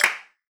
Clap
Original creative-commons licensed sounds for DJ's and music producers, recorded with high quality studio microphones.
Clap F# Key 15.wav
clap-sound-clip-f-sharp-key-24-gOV.wav